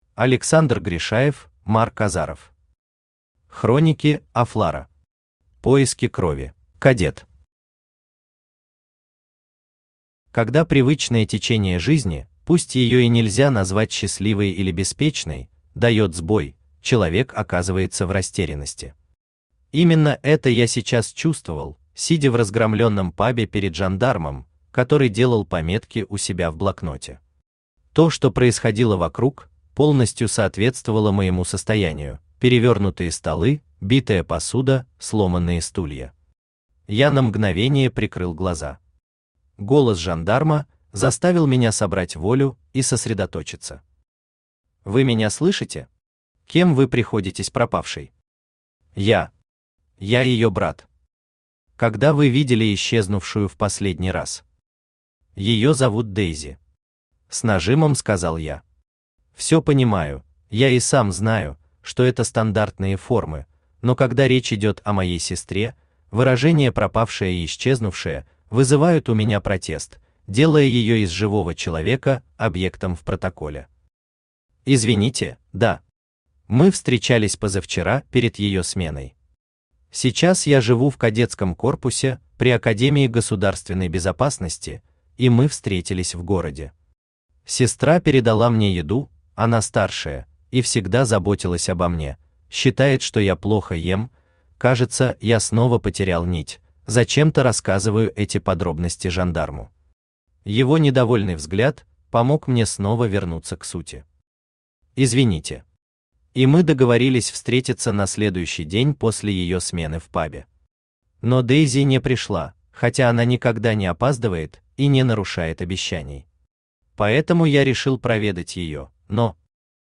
Поиски крови Автор Александр Гришаев Читает аудиокнигу Авточтец ЛитРес.